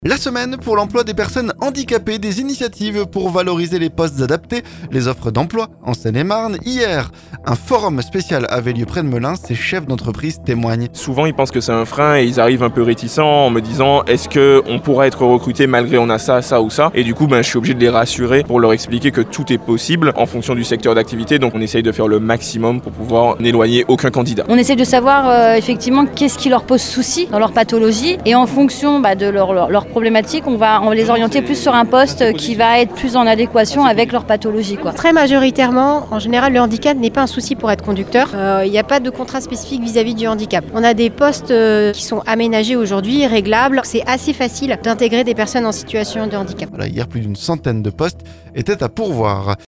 La semaine pour l'emploi des personnes handicapées, avec des initiatives pour valoriser les postes adaptés et les offres d'emploi en Seine-et-Marne. Mardi, un forum spécial avait lieu près de Melun. Ces chefs d'entreprises témoignent.